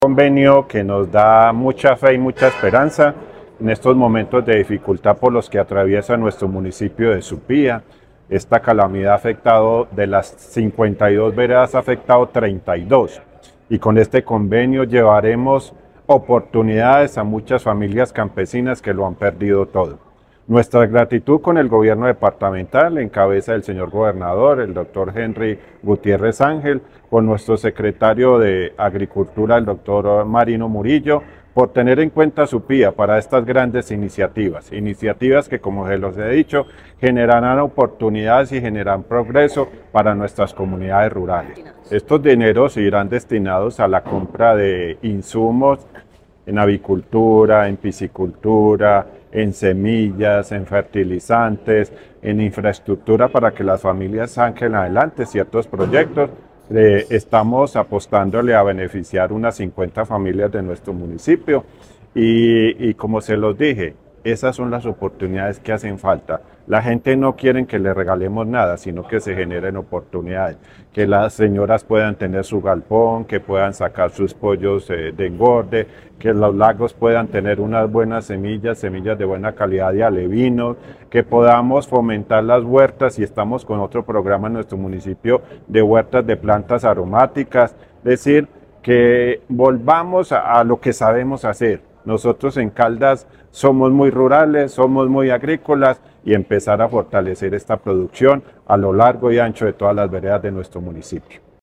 Héctor Mauricio Torres, alcalde de Supía.